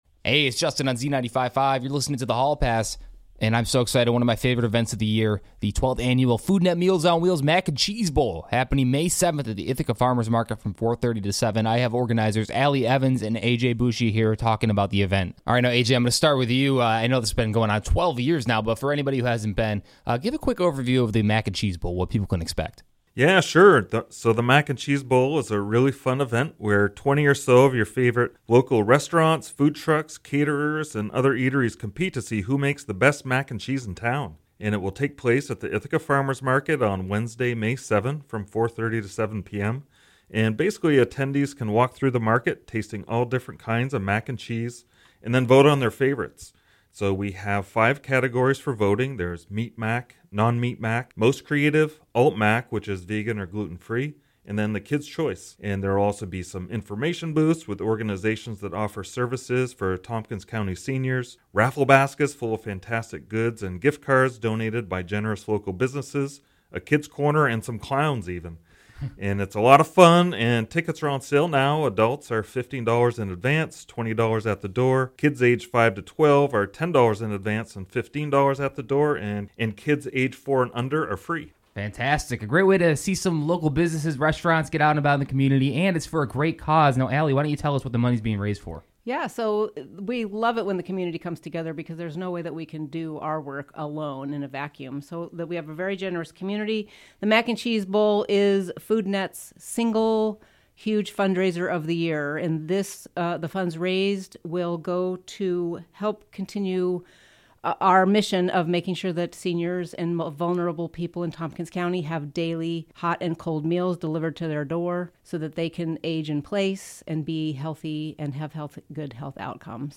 Foodnet-Mac-and-Cheese-Bowl-Interview-2025.mp3